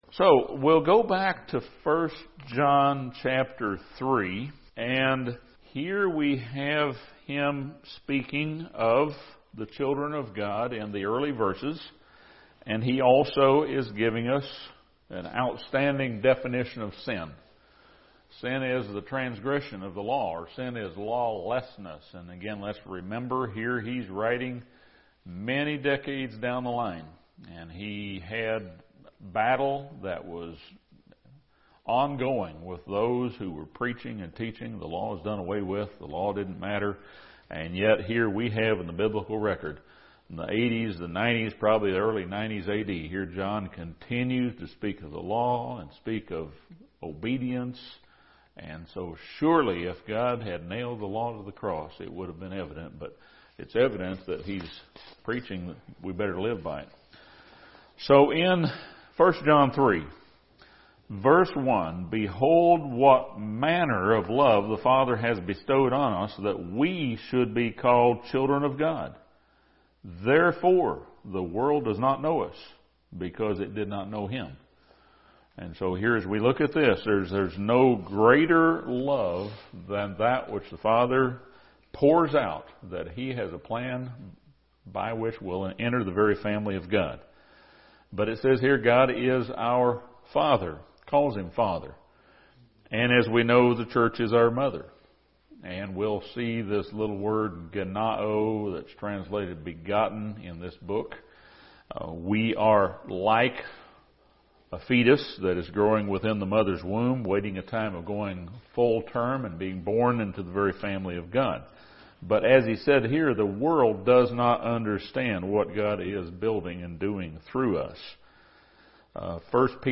This Bible study surveys 1 John:3:1 through 1 John:4:6.